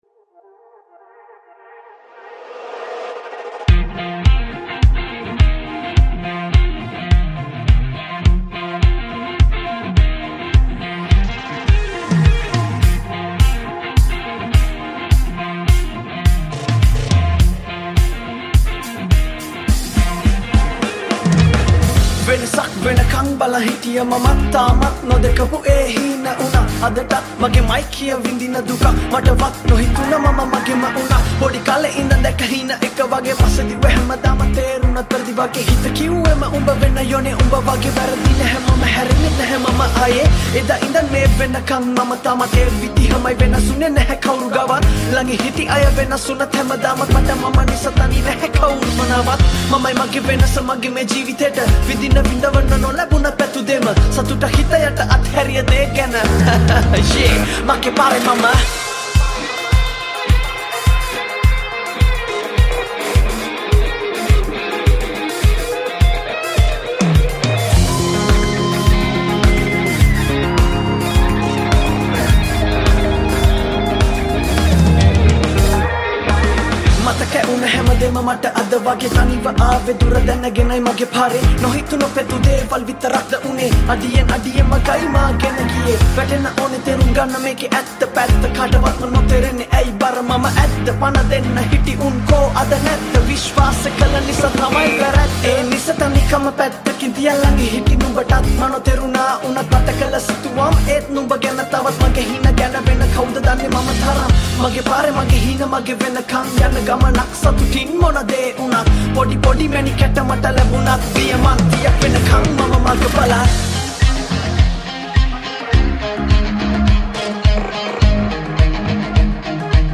Electric Guitar
Bass Guitar
Drums